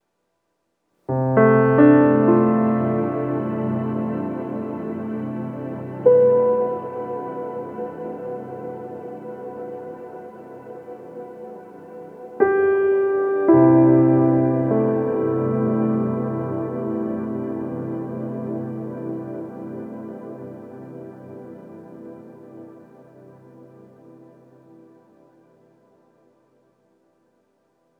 Reverb Piano 05.wav